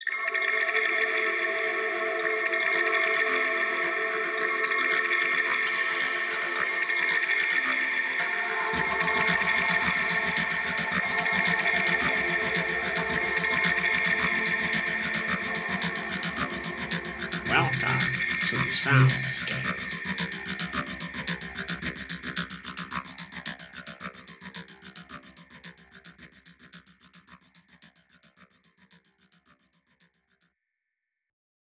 The sounds I used were recordings of my toilet flushing, the washing machine, doors, a spray-can and my voice.
I edited parts of the audio from my raw recordings and I applied some delay and reverb effects, as well as vocoder effects. The recordings were made using my phone, and the finishing touches were done in digital audio workstations called Propellerhead Reason and FL studio.
soundscape_master.wav